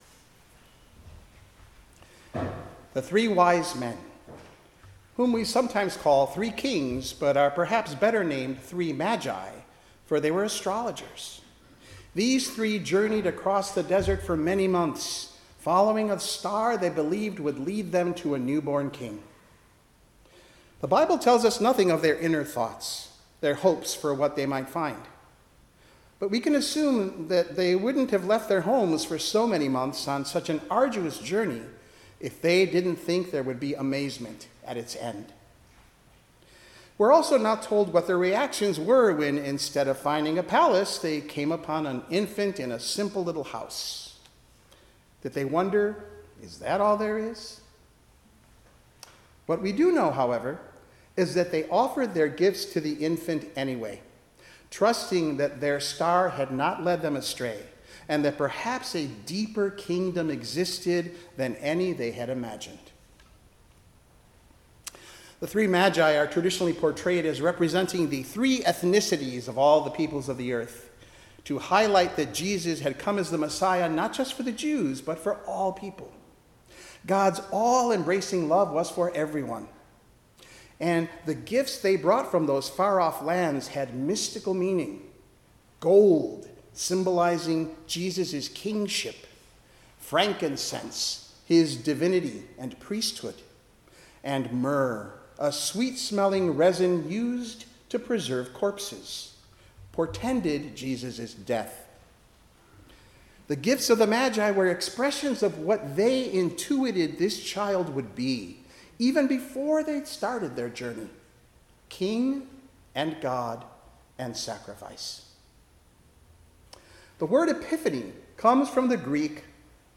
Sermon-Epiphany-January-6-2026_edit.mp3